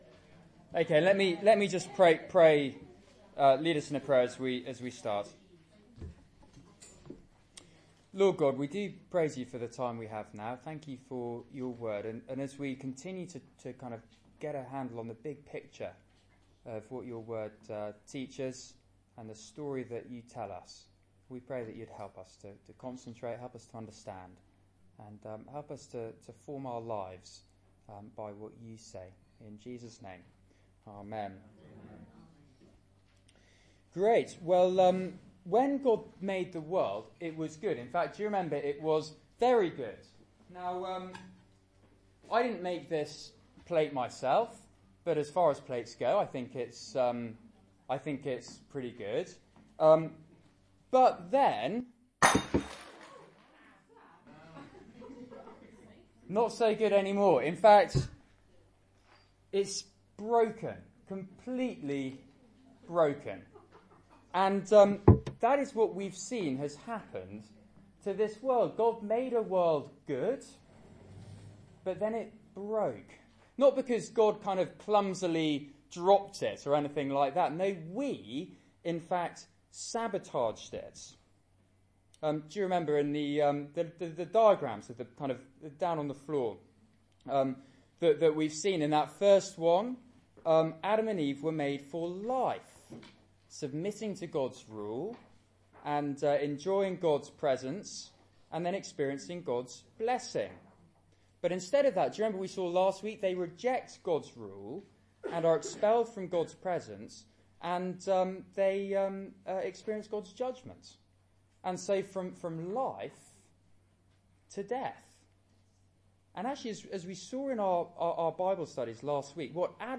Seminar